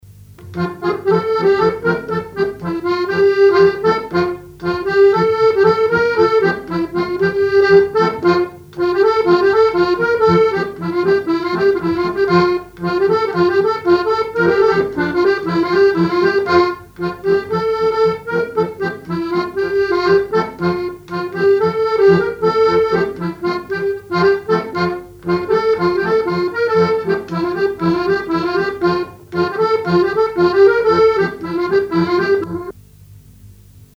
danse : gigouillette
accordéon chromatique
Pièce musicale inédite